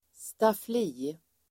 Ladda ner uttalet
staffli substantiv, easel Uttal: [stafl'i:] Böjningar: staffliet, stafflier, stafflierna Definition: ställning där målare ställer tavla under arbete easel substantiv, staffli Förklaring: ställning där målare ställer tavla under arbete